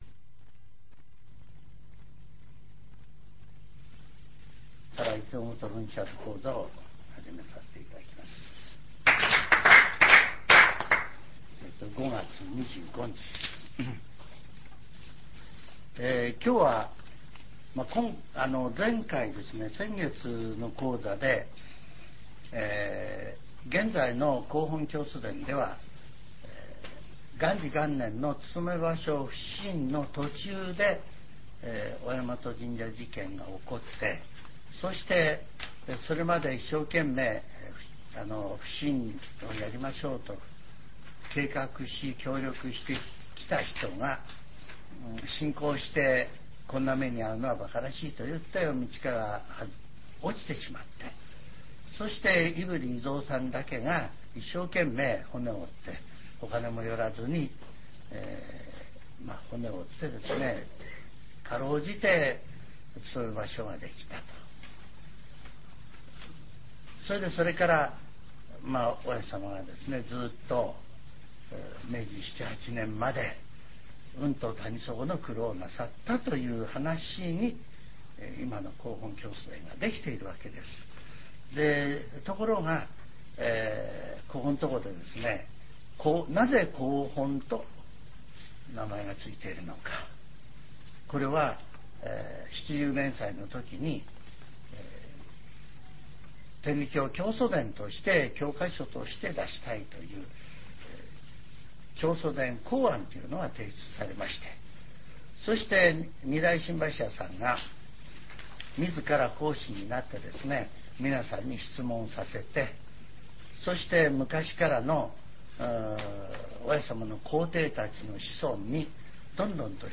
全70曲中21曲目 ジャンル: Speech